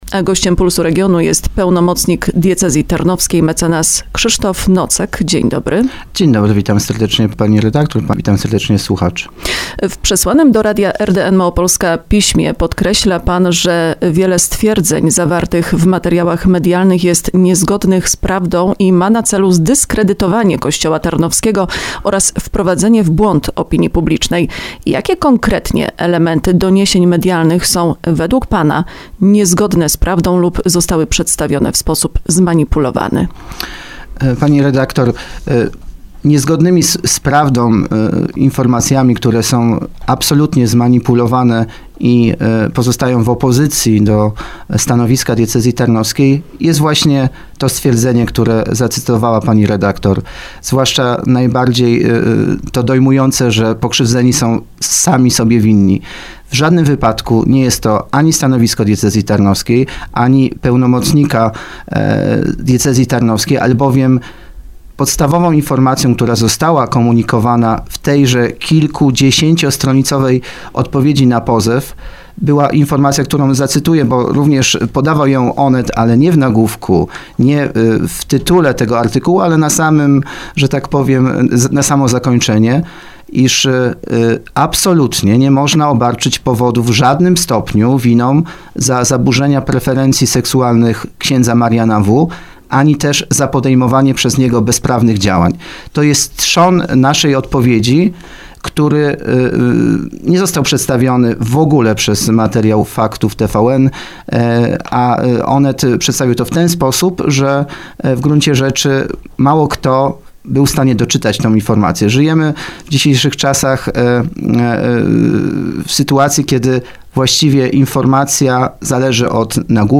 28PulsRozmowa.mp3